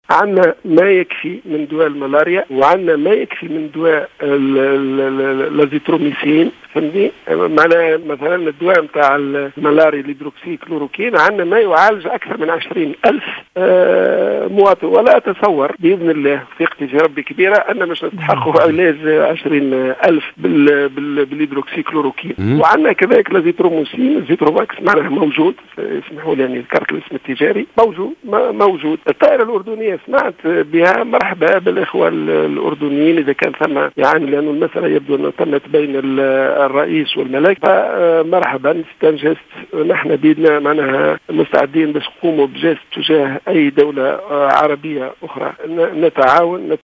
أكد وزير الصحة عبد اللطيف المكي في مداخلة له صباح اليوم على الإذاعة الوطنية أن وزارة الصحة التونسية لديها من دواء الكلوروكين ما يكفي لعلاج 20 ألف مصاب بالكورونا .